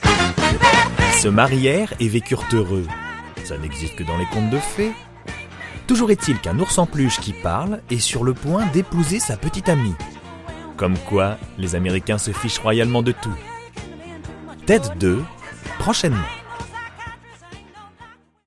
Bande annonce
Ted 2 : voix medium et souriante